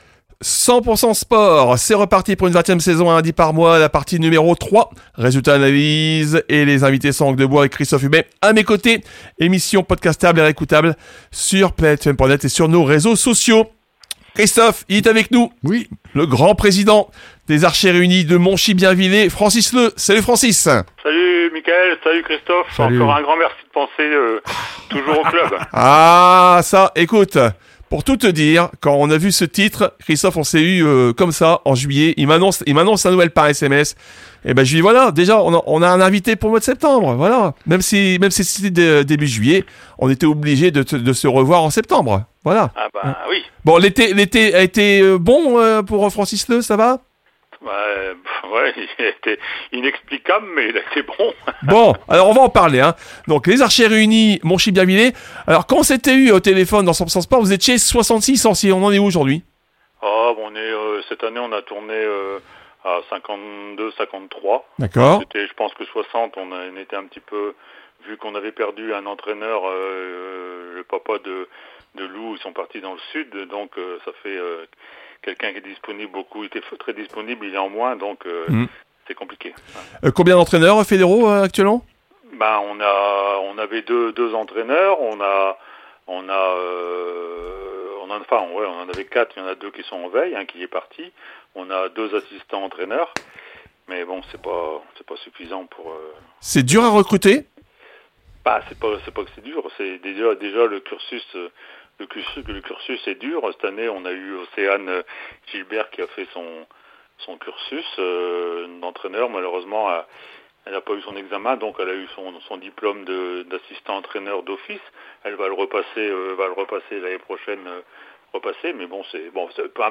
Vous pouvez (ré)écouter l’interview ici 👉 ( 100% SPORT)